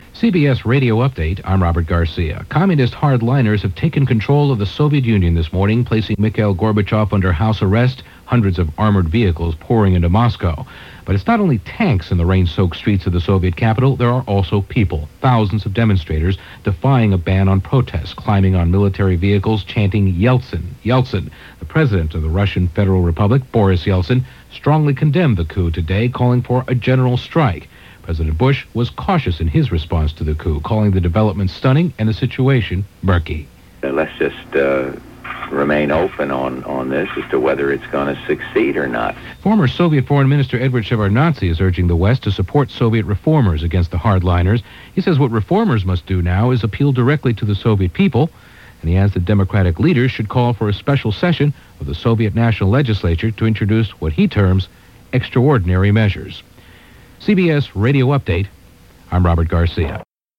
CBS Radio News – Special Report
Here is one of those bulletins as given by CBS Radio News on August 19, 1991.